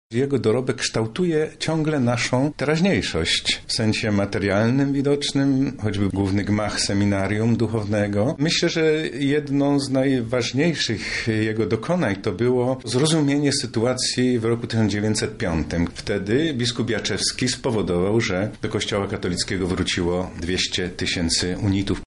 – Wciąż odkrywamy nowe fakty dotyczące tego kapłana – mówi arcybiskup Stanisław Budzik, metropolita lubelski